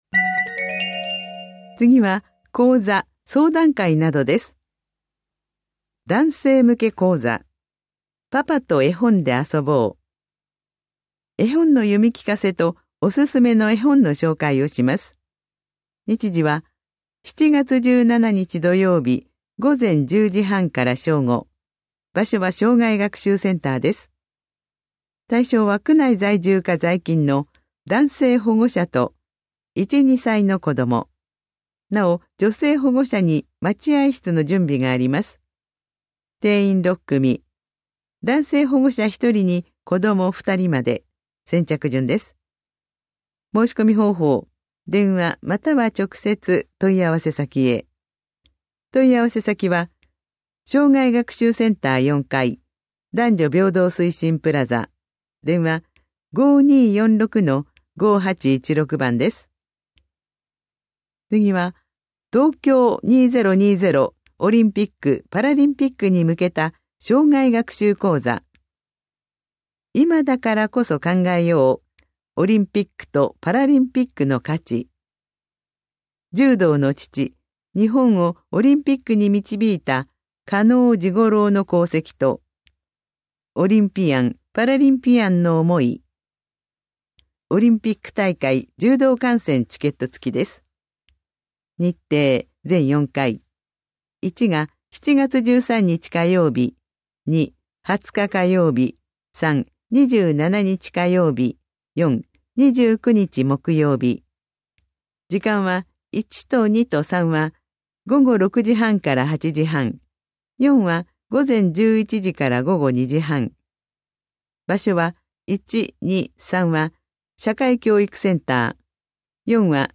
広報「たいとう」令和3年6月20日号の音声読み上げデータです。